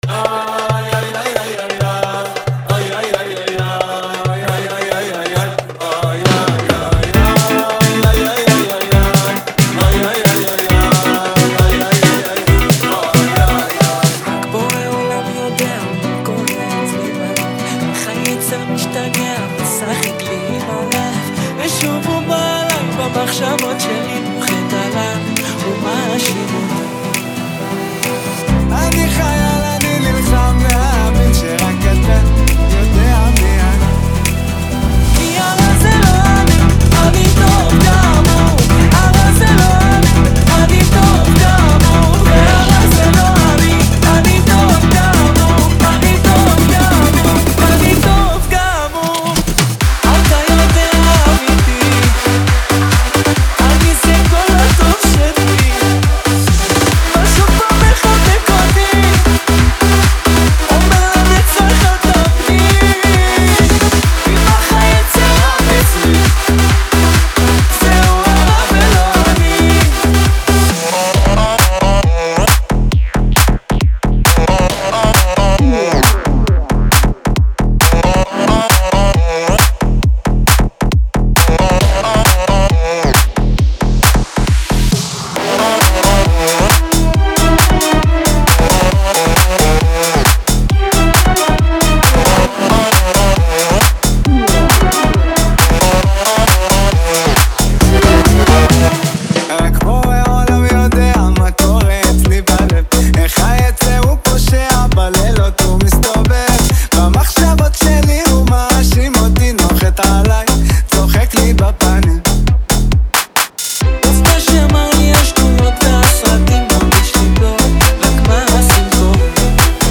(זה לא עבר מיקס)